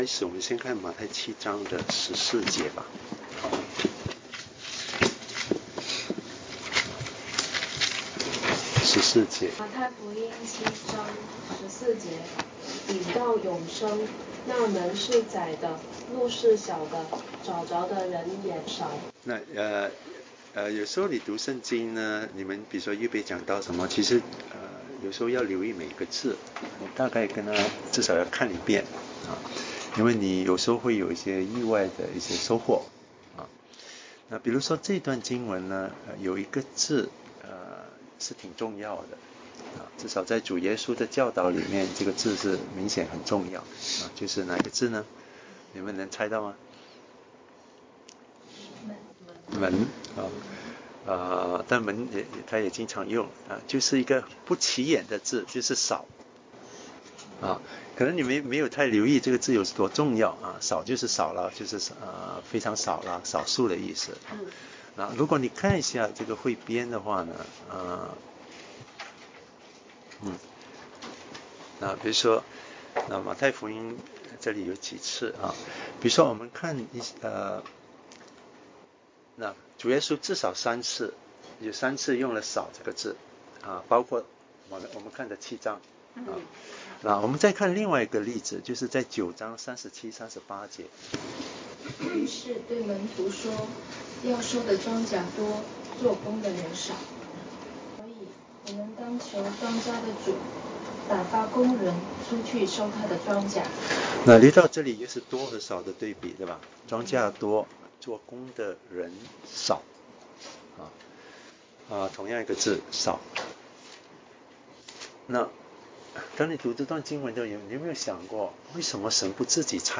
信息